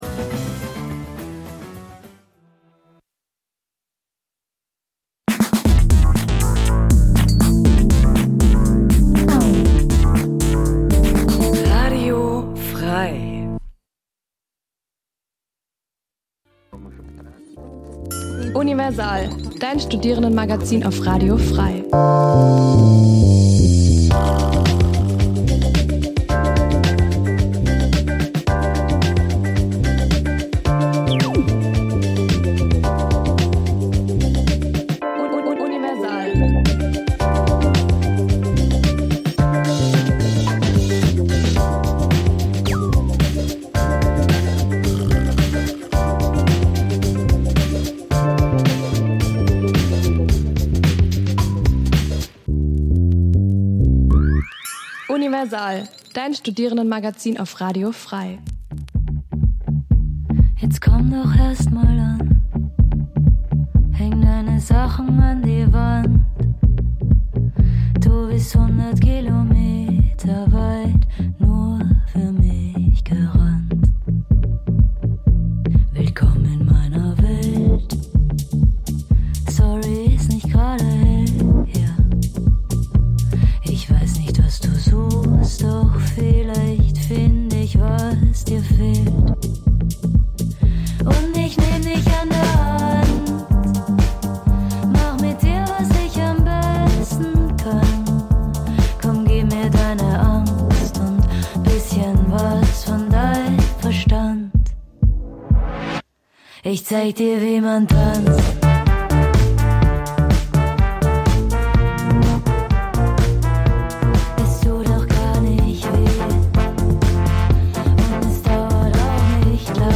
Die Sendungen werden gemeinsam vorbereitet - die Beitr�ge werden live im Studio pr�sentiert.